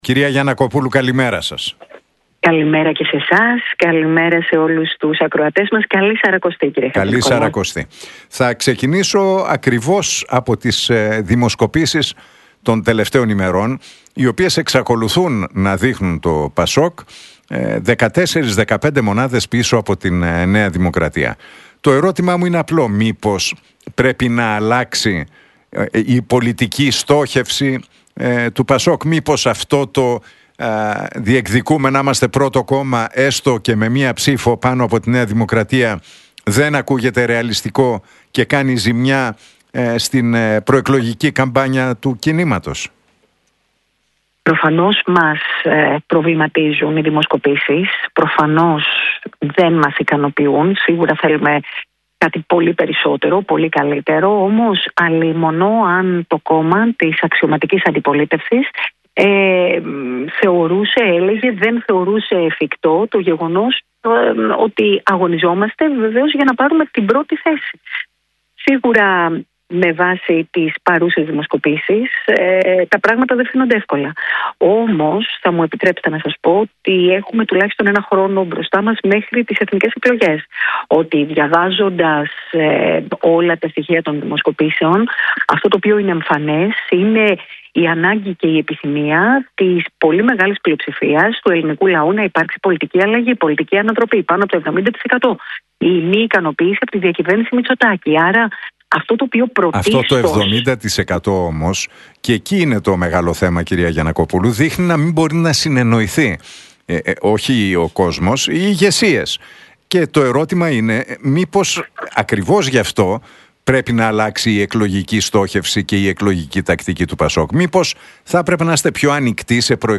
Η Νάντια Γιαννακοπούλου στον Realfm 97,8 μιλάει για την ηγεσία του ΠΑΣΟΚ και σχολιάζει τις δημοσκοπήσεις